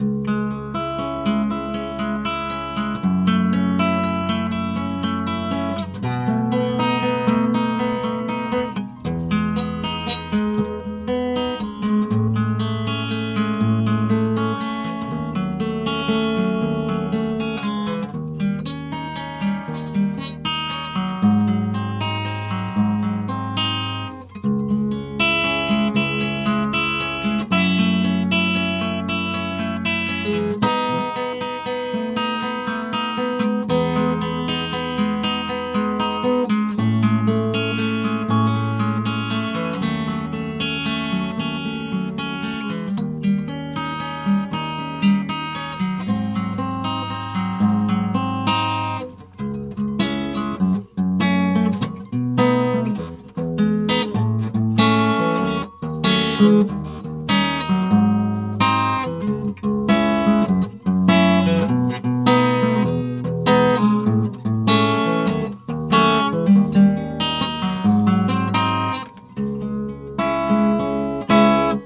Вот все которые сталкеры играют у костра.